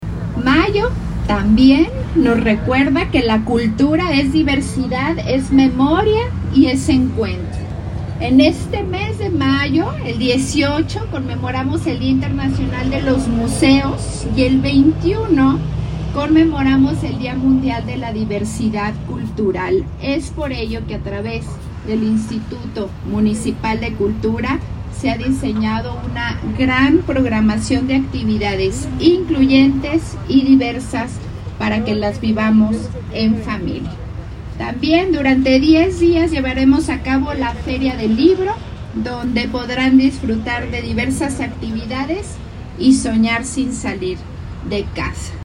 Durante este acto cívico autoridades de la Secretaría de la Defensa Nacional (Sedena) realizaron el izamiento de la bandera nacional y rindieron honores al lábaro patrio.